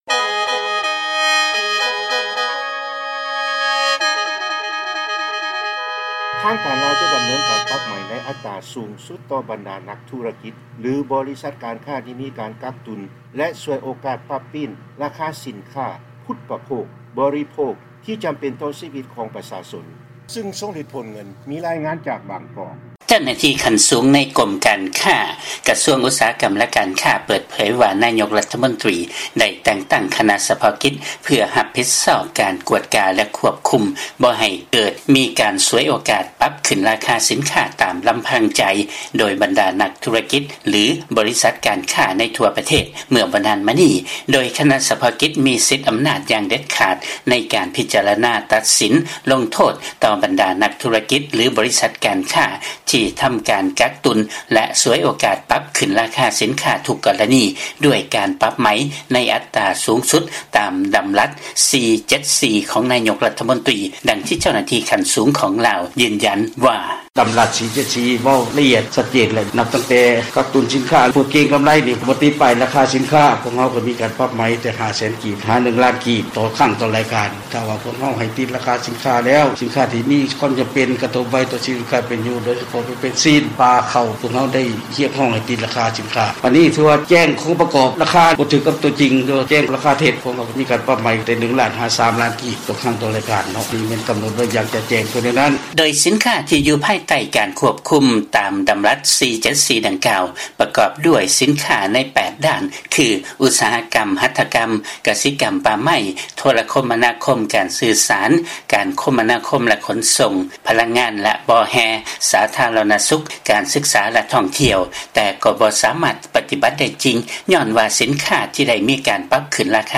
ເຊີນຟັງລາຍງານ ທາງການລາວ ຈະປັບໃໝໃນອັດຕາສູູງສຸດ ຕໍ່ບັນດານັກທຸລະກິດຫຼືບໍລິສັດການຄ້າ ທີ່ກັກຕຸນແລະສວຍໂອກາດປັບຂຶ້ນລາຄາສິນຄ້າ